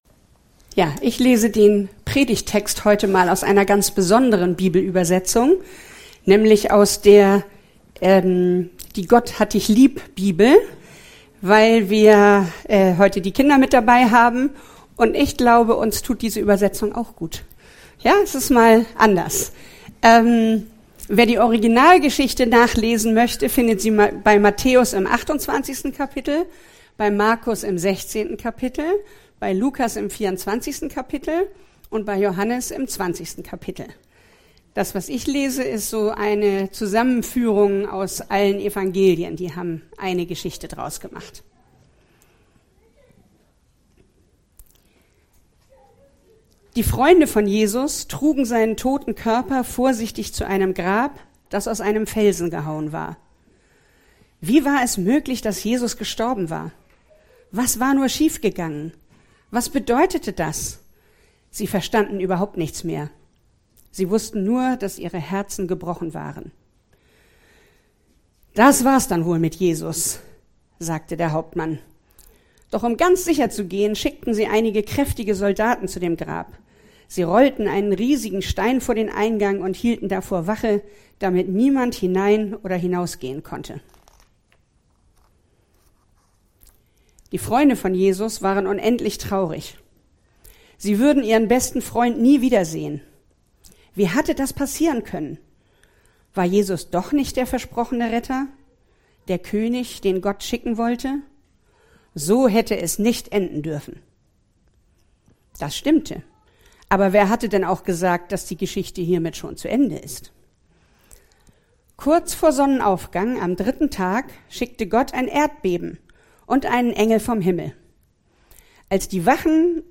Serie: Predigt Gottesdienst: Sonntag